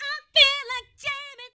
05 RSS-VOX.wav